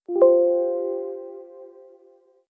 background-error.wav